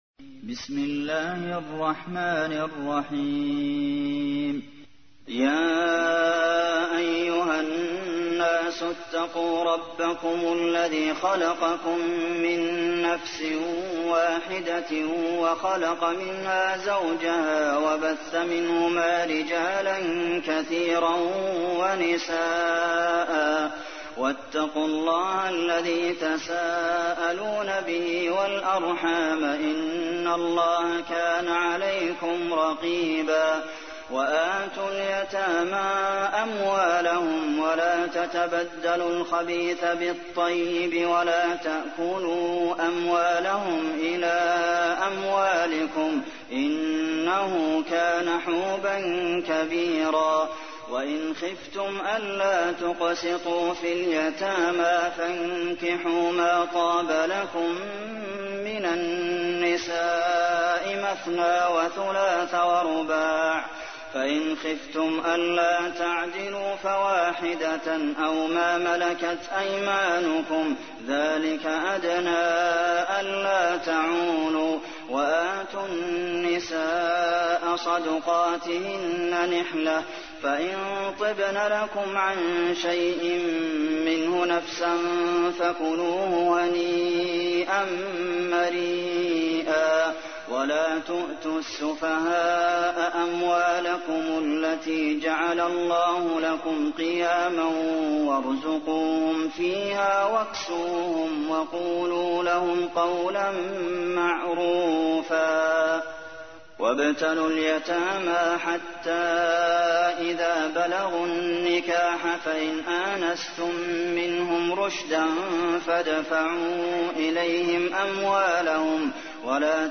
تحميل : 4. سورة النساء / القارئ عبد المحسن قاسم / القرآن الكريم / موقع يا حسين